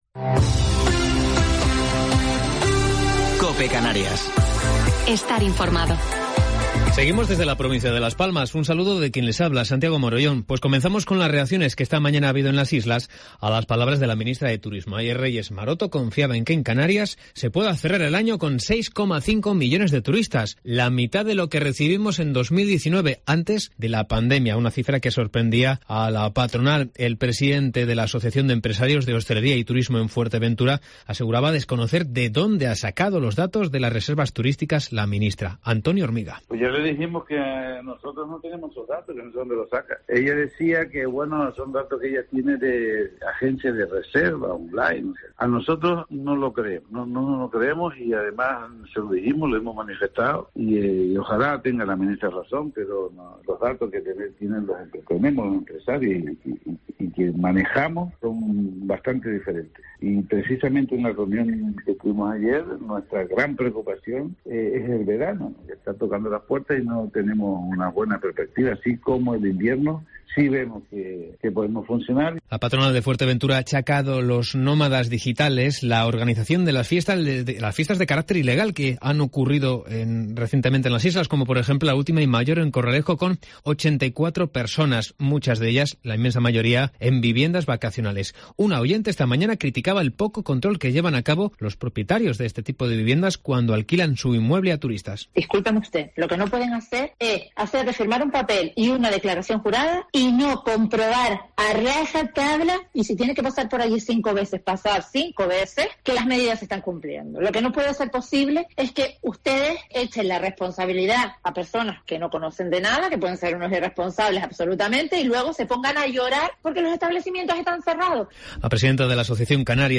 Informativo local 20 de Abril del 2021